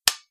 switch1.wav